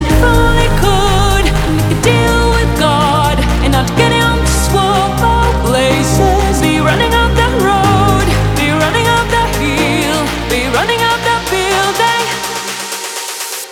• Качество: 321, Stereo
remix
спокойные
house
нежные